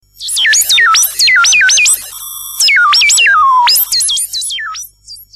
Oberheim - Matrix 1000 7